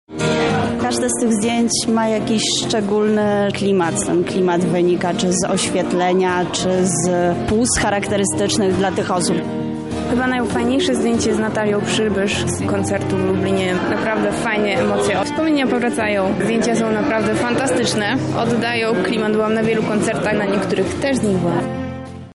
Swoimi wrażeniami z wernisażu podzielili się widzowie.